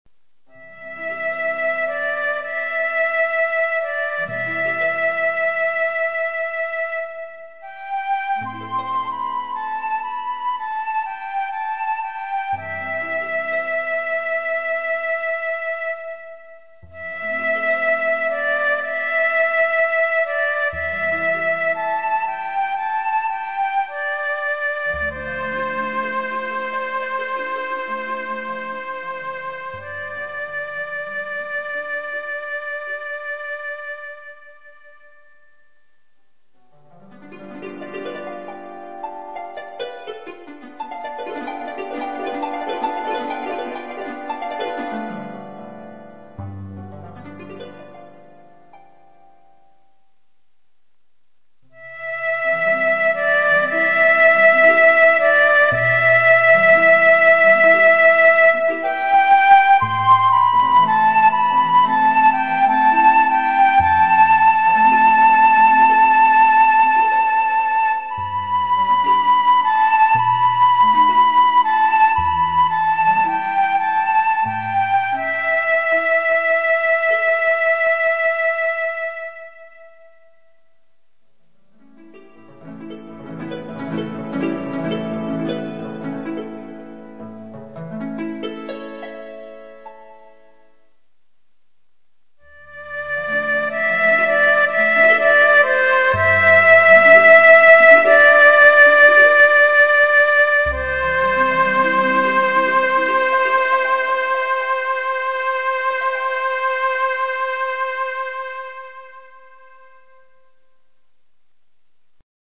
mp3-dwonload、新婦退場はハープの音色